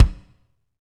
Index of /90_sSampleCDs/Northstar - Drumscapes Roland/DRM_Medium Rock/KIT_M_R Kit 2 x
KIK M R K03R.wav